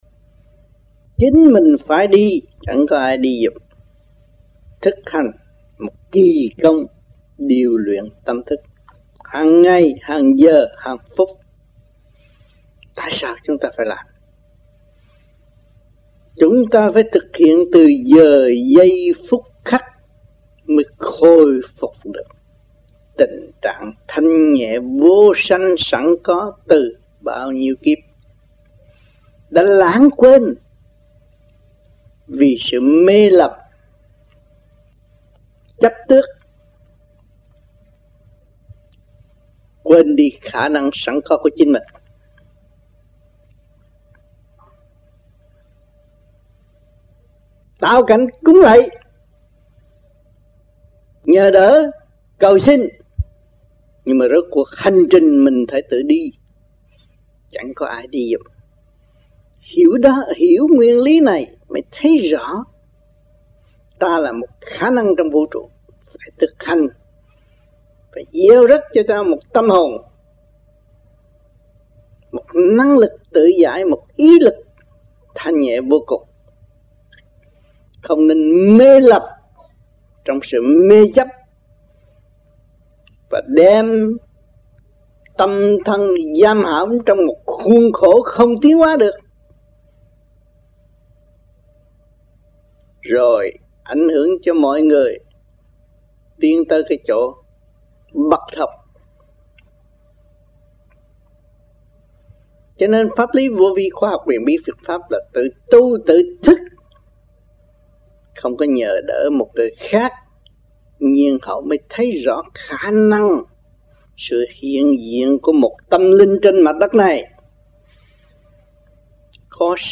1992 Khóa Học Tại Thìền Viện Vĩ Kiên
1992-02-22 - TV VĨ KIÊN - THUYẾT PHÁP